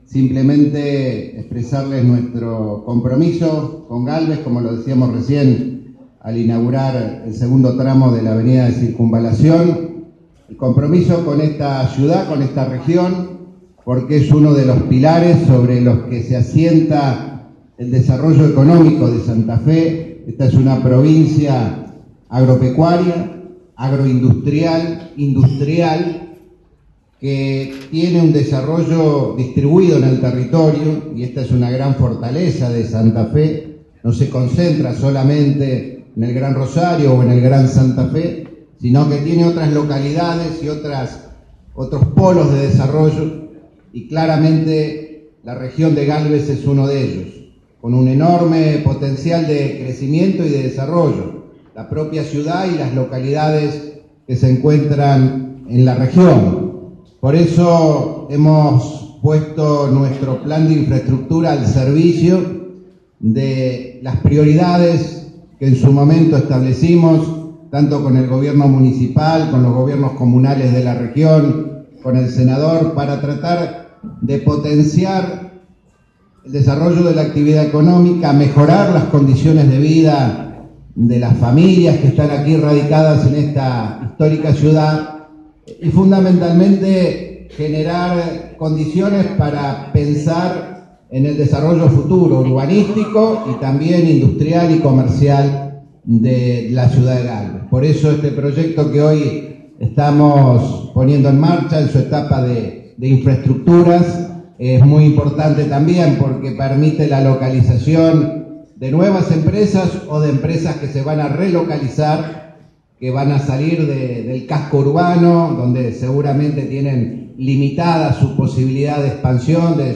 En el acto desarrollado en la Casa de la Historia y la Cultura de esa ciudad, el gobernador Miguel Lifschitz expresó "el compromiso con Gálvez y con esta región, porque es uno de los pilares sobre los que se asiente al desarrollo económico de Santa Fe".
Discurso del gobernador Miguel Lifschitz